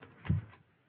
destruirFoto.ogg